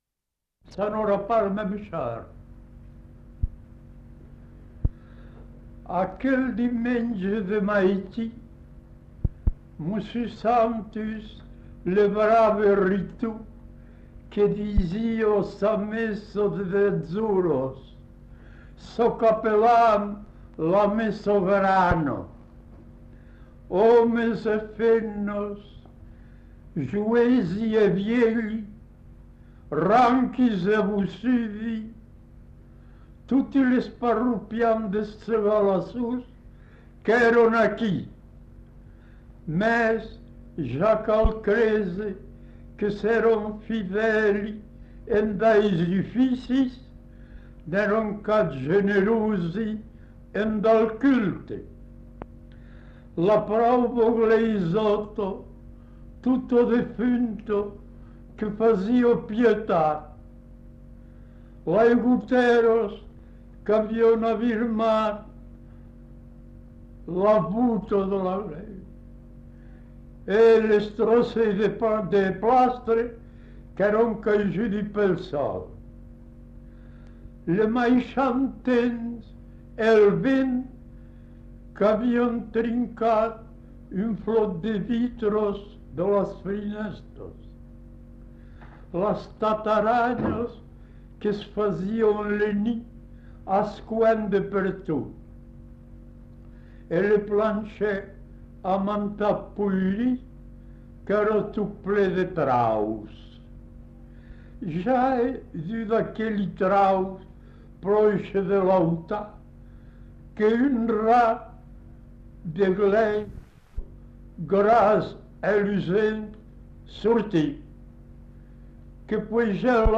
Aire culturelle : Couserans
Genre : conte-légende-récit
Effectif : 1
Type de voix : voix d'homme
Production du son : parlé